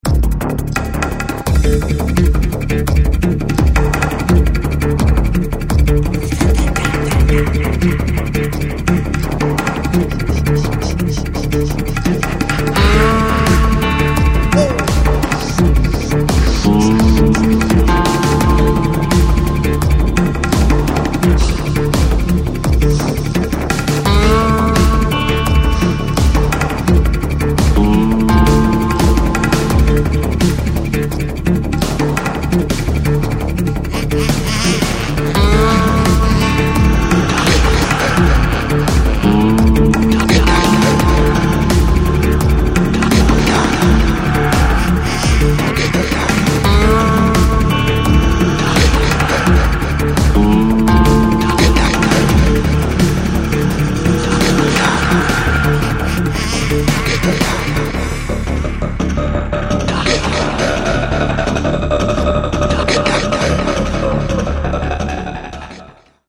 IDM/Electronica, Trance